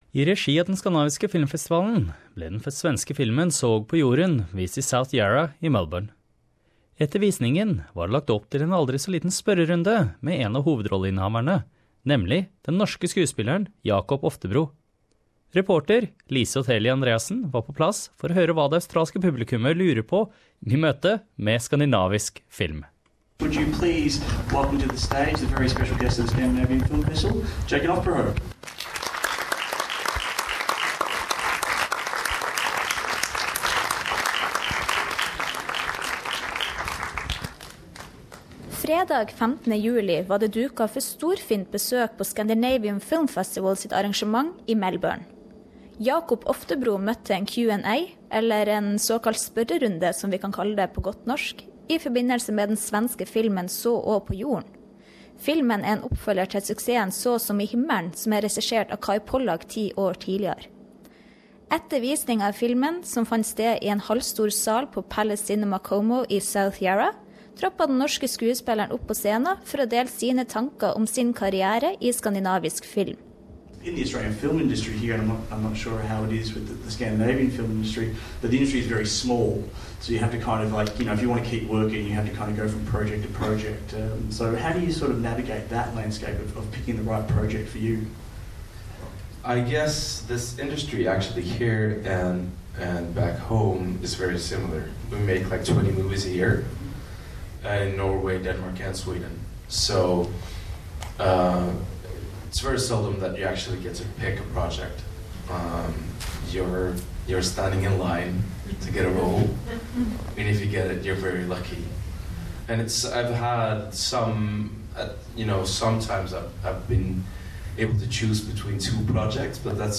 After a screening of Heaven on Earth in Melbourne actor Jakob Oftebro answered questions from the movie goers.